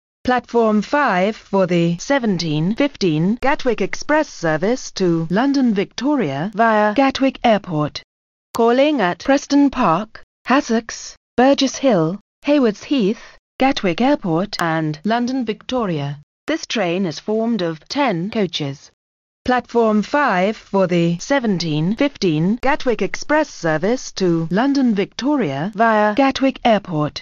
An assembled announcement of a Gatwick Express service to London Victoria departing at 17:15 departing from Platform 5.